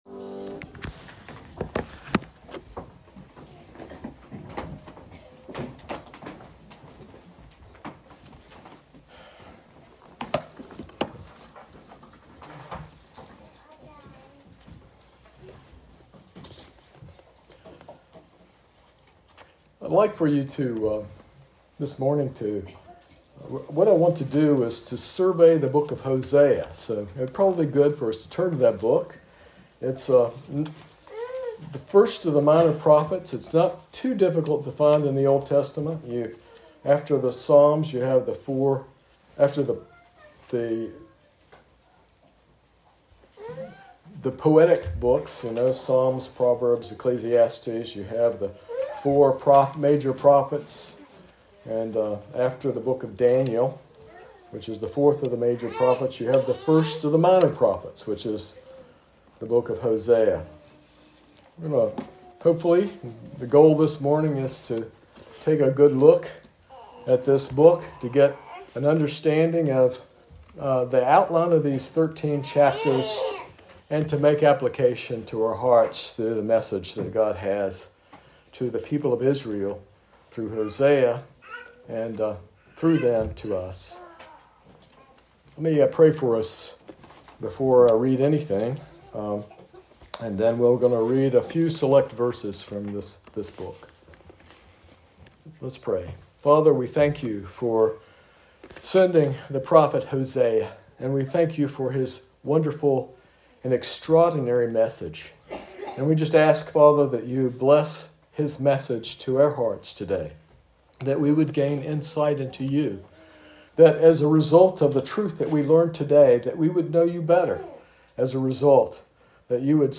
This sermon surveys the book of Hosea, exploring God's covenant love (chesed) and Israel's unfaithfulness, mirrored in Hosea's marriage to Gomer. It highlights God's judgment and ultimate redemption, foreshadowing Christ's sacrifice to purchase us from the slave market of sin.